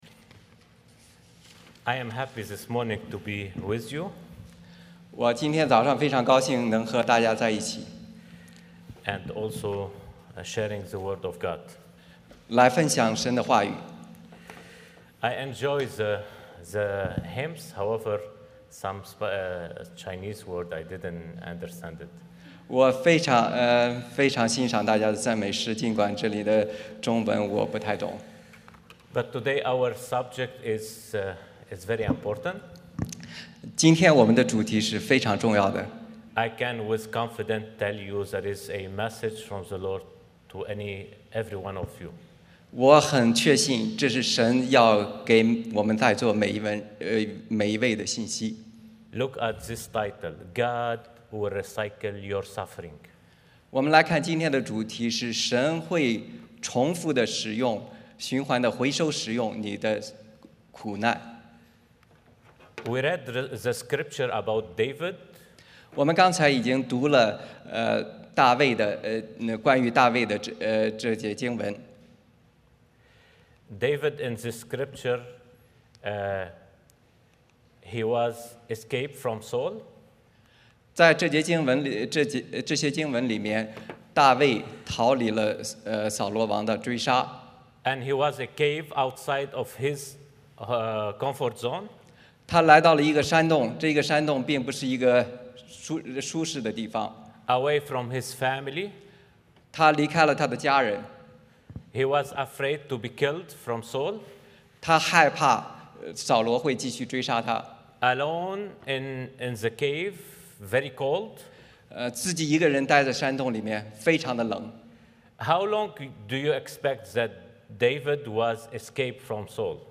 撒母耳记上 22:1-5 Service Type: 主日崇拜 欢迎大家加入我们的敬拜。